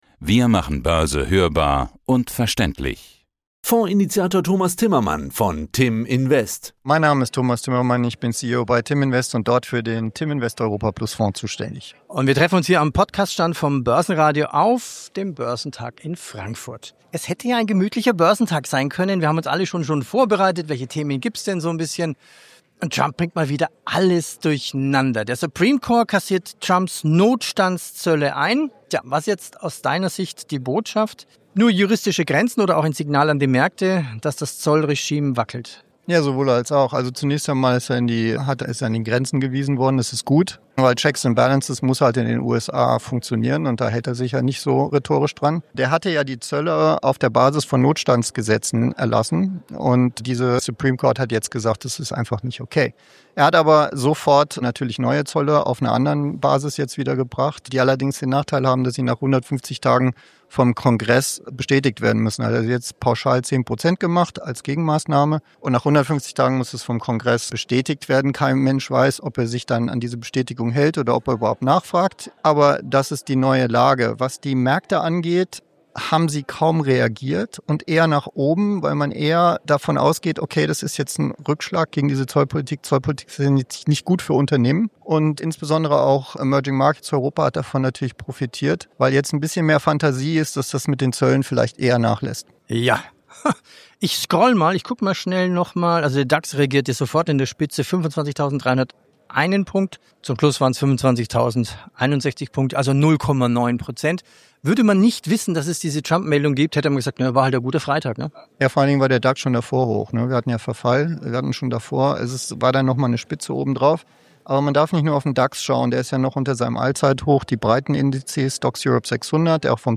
auf dem Frankfurter Börsentag